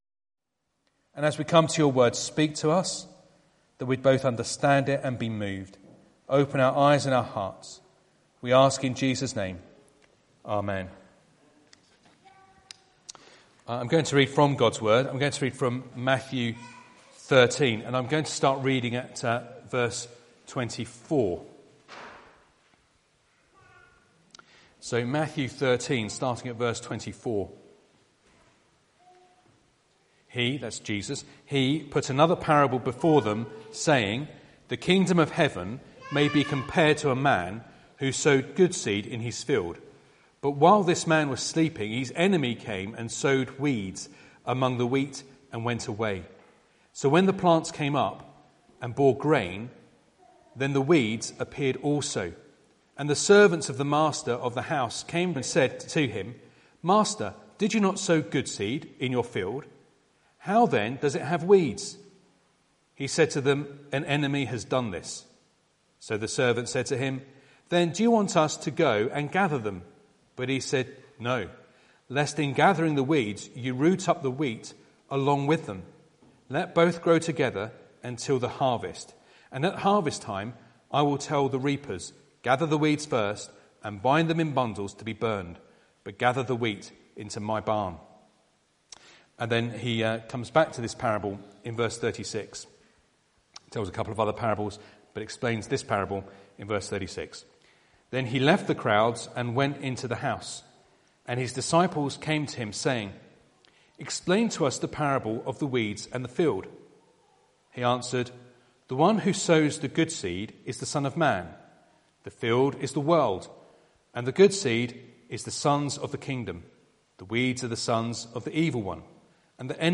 Harvest Passage: Matthew 13:24-30, 36-43 Service Type: Sunday Morning « 3.